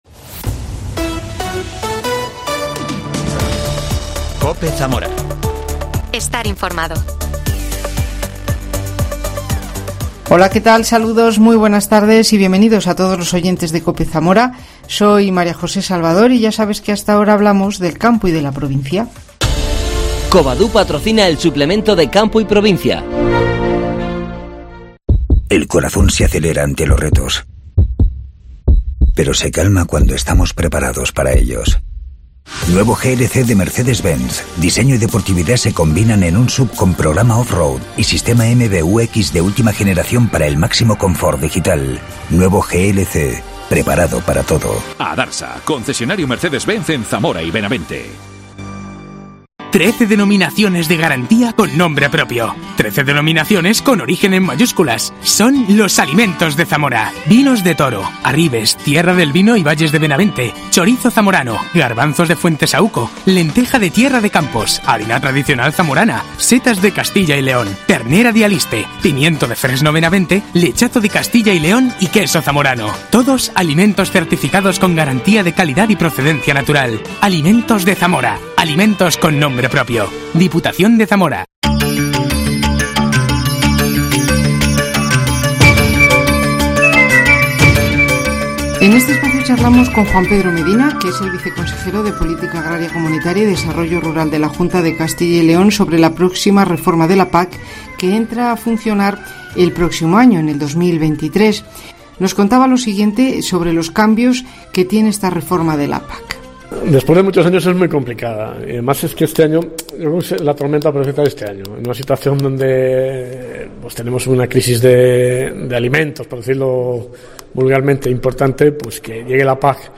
AUDIO: Entrevista al viceconsejero de Politica Agraria de la Junta, Juan Pedro Medina